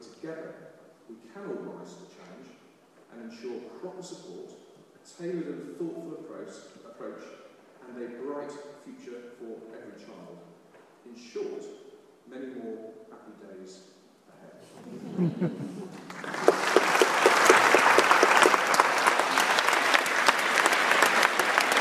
Edward Timpson addresses the Achievement For All conference - which also included a live assembly by Henry Winkler (also known as 'The Fonz' from Happy Days). Achievement for All is an independent charity raising the aspirations, access and achievement of vulnerable & disadvantaged children and young people.